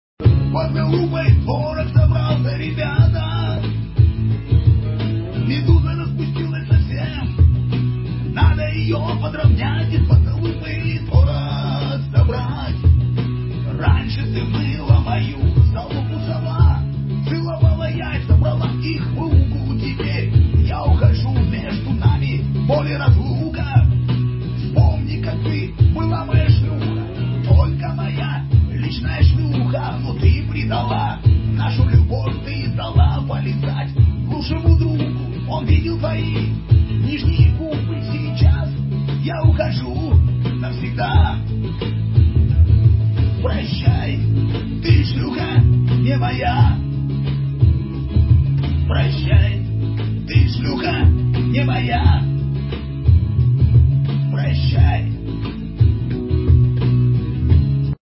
pdpodpod Meme Sound Effect